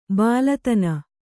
♪ bālatana